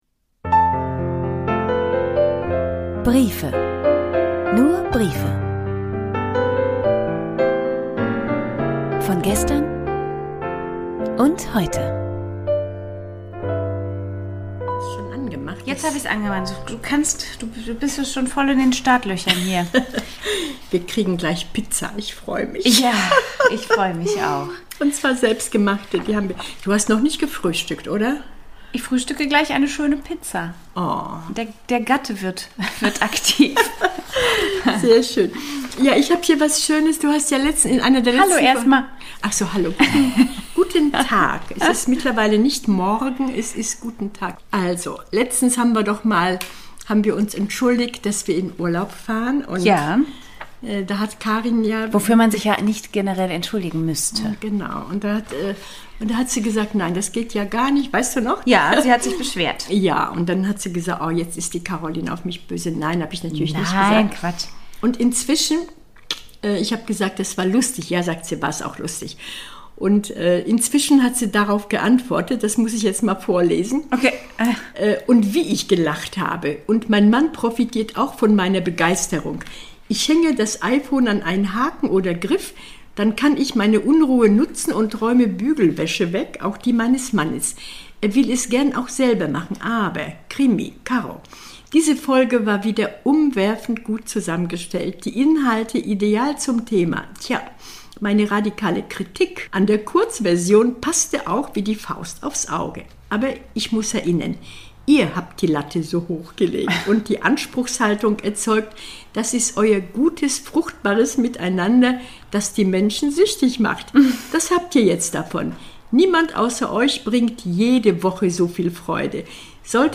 Und wenn's hinten klappert, dann isses der Gatte.